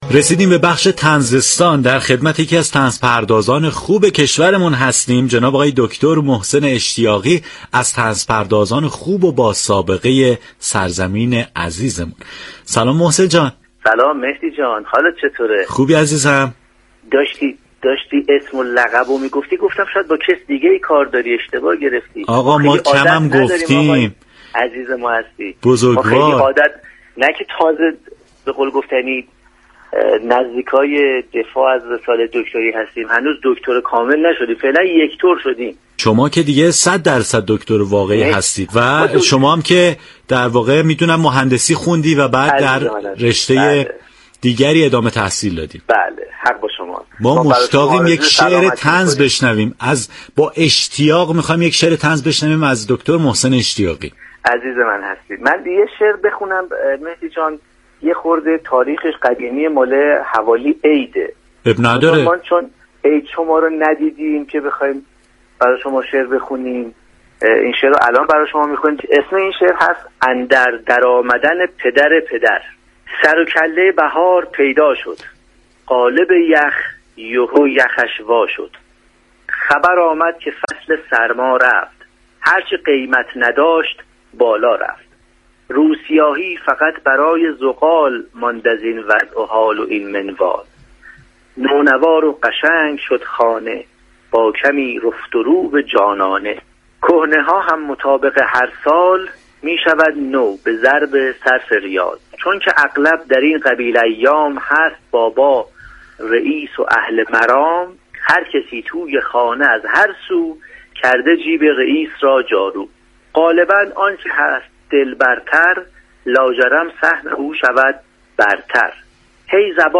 گفتگوی رادیو صبا